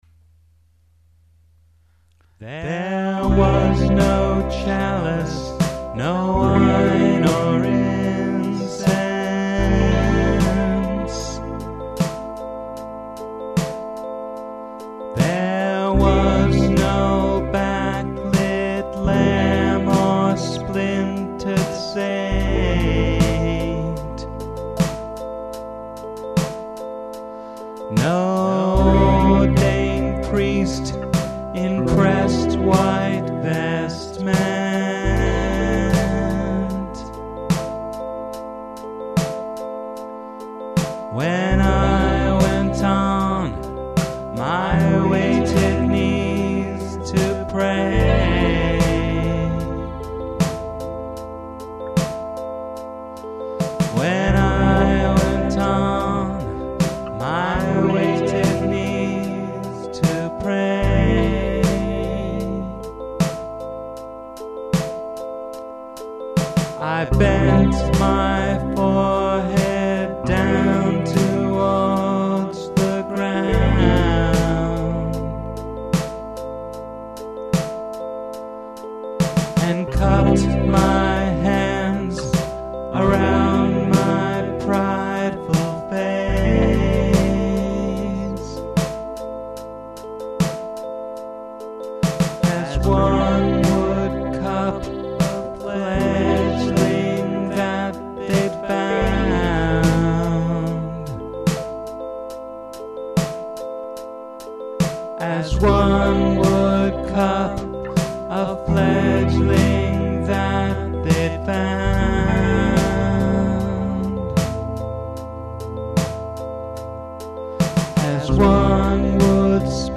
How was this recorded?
Here’s the first mix. No reverb. It may even be mono.